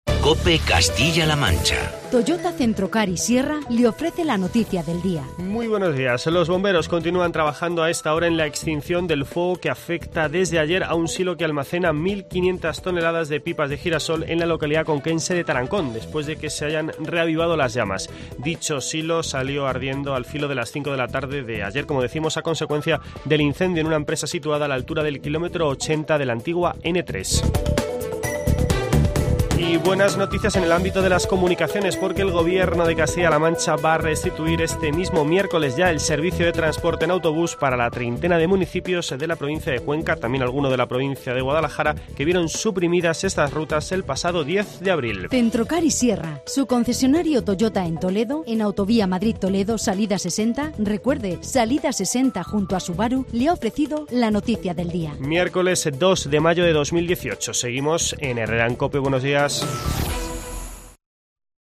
Boletín informativo de COPE Castilla-La Mancha.